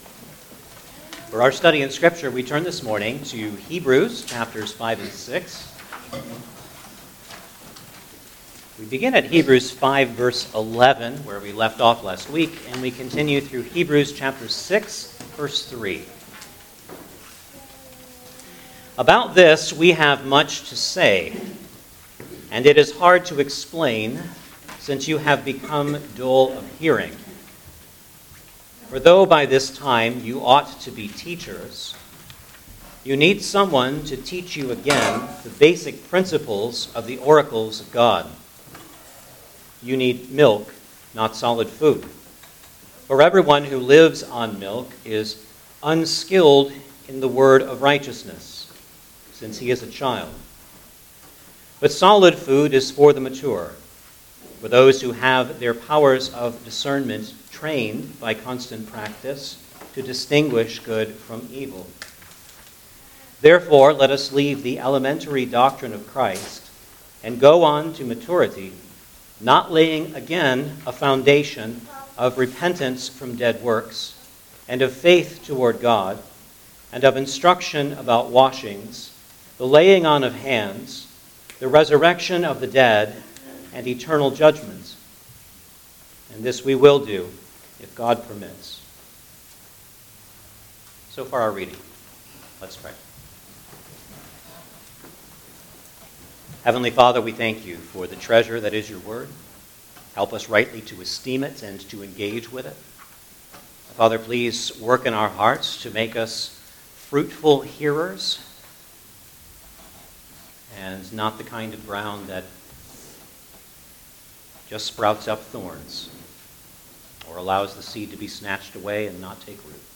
Hebrews Passage: Hebrews 5:11 – 6:3 Service Type: Sunday Morning Service Download the order of worship here .